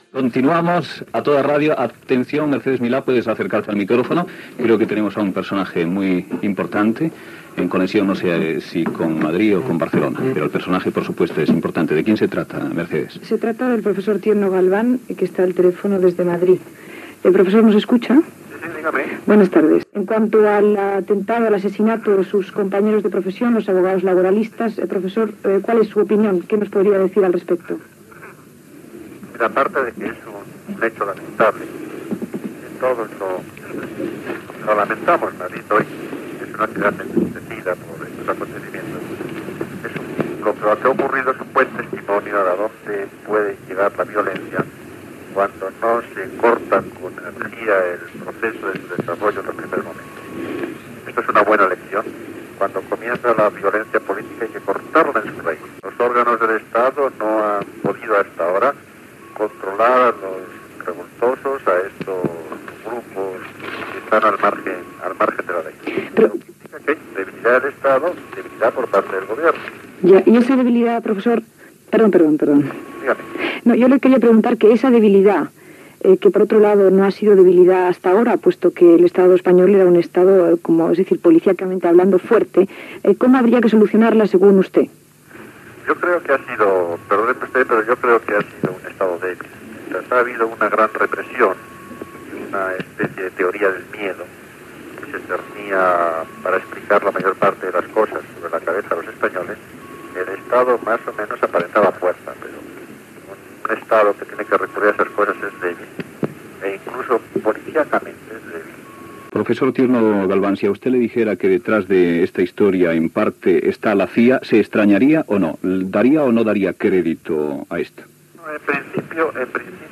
Identificació del programa i entevista a Enrique Tierno Galván del Partido Socialista Popular pocs dies després de l'assasinat d'advocats al carrer Atocha de Madrid
Info-entreteniment